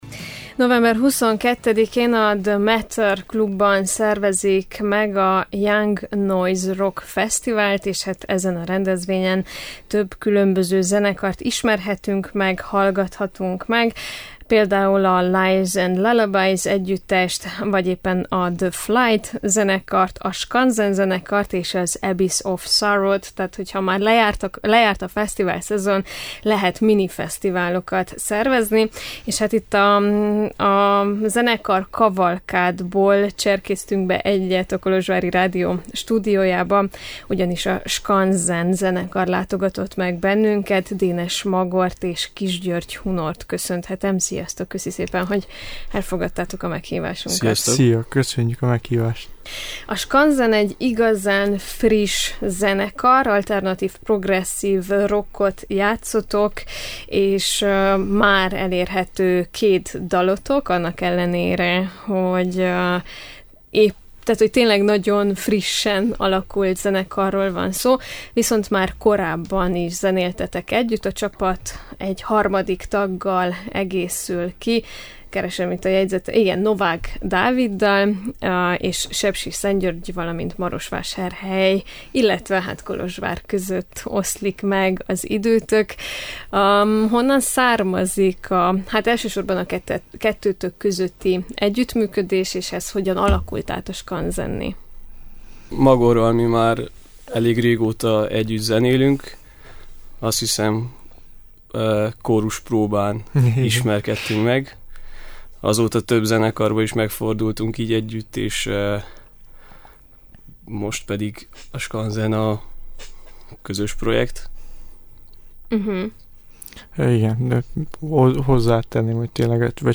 Pénteken a Young Noise Rock Festivalon koncertezik a Skanzen zenekar, akiket a Rock and Roll FM műsorunkban láttunk vendégül.